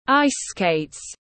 Giày trượt băng tiếng anh gọi là ice skates, phiên âm tiếng anh đọc là /ˈaɪs skeɪt/
Ice-skates-.mp3